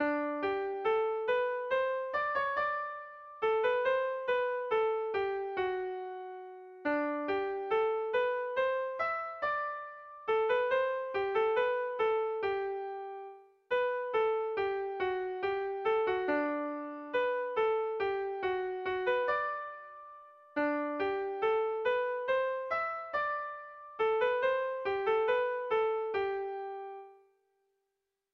Erlijiozkoa
Zortziko txikia (hg) / Lau puntuko txikia (ip)
AABA